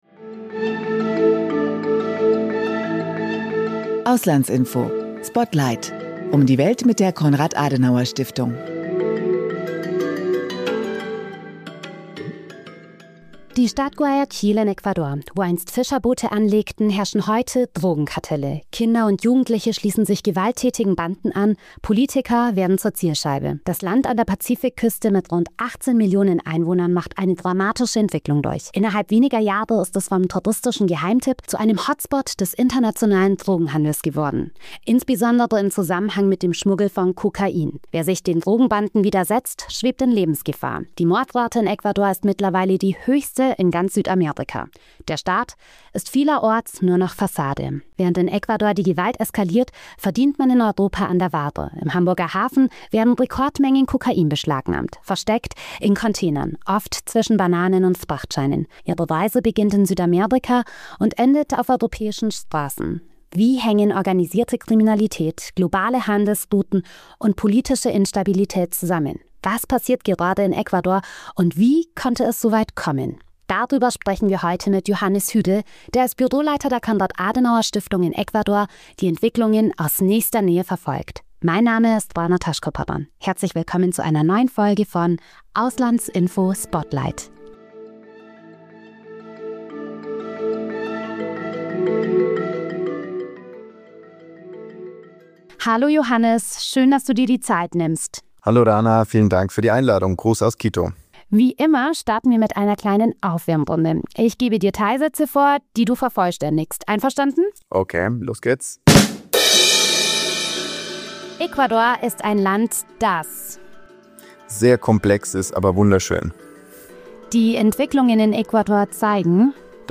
Ihre Expertinnen und Experten erklären internationale Politik und geben im Gespräch Hintergrundinformationen zu aktuellen Entwicklungen und Ereignissen.